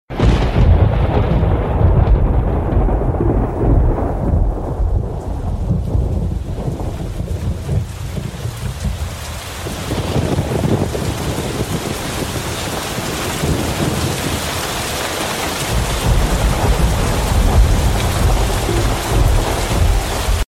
grom-i-dozhd_24579.mp3